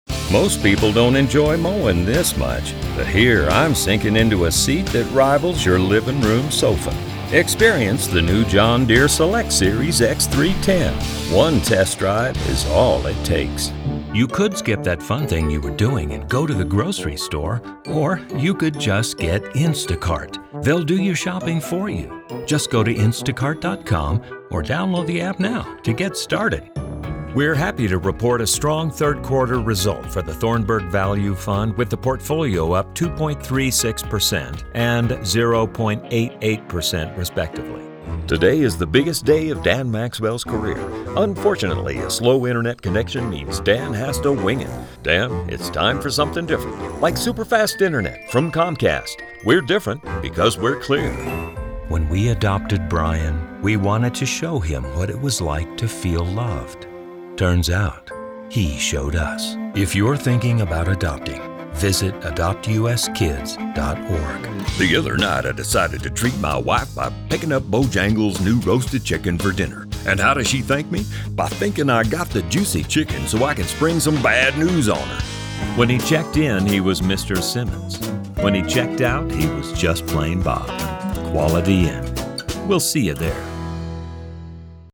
Voiceover Artist
Conversational • Friendly • Honest • Intimate • Warm Storyteller • Cheesy Announcer • Characters
Commercial Demo